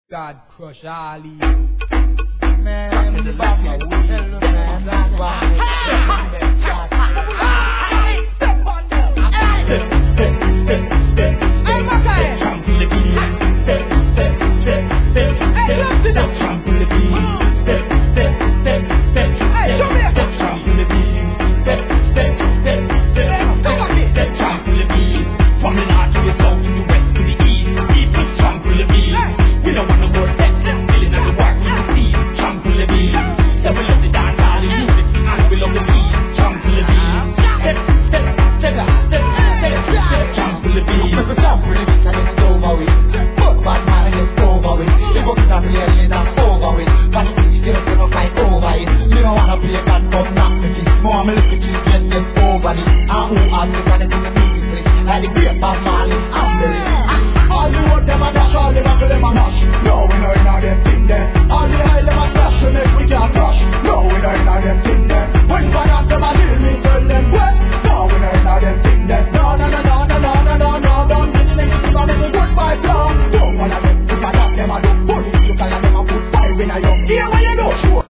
REGGAE
ダンスネタ!